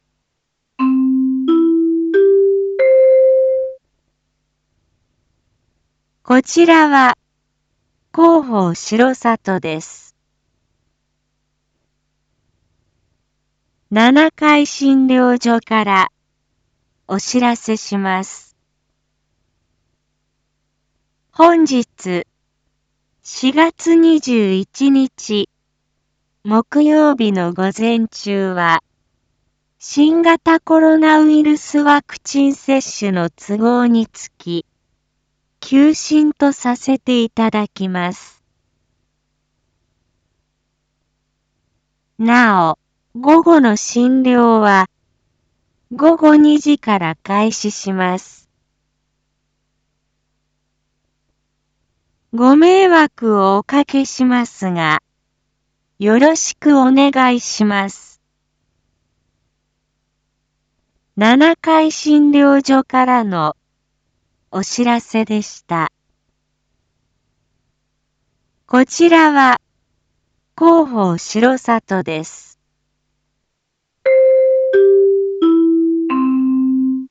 一般放送情報
Back Home 一般放送情報 音声放送 再生 一般放送情報 登録日時：2022-04-21 07:01:18 タイトル：R4.4.21 7時 放送分 インフォメーション：こちらは広報しろさとです。